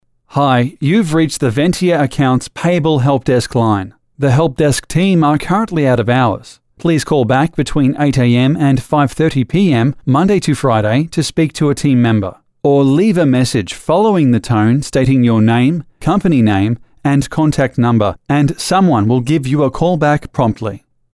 Professional, Natural, Conversational
englisch (australisch)
Sprechprobe: Sonstiges (Muttersprache):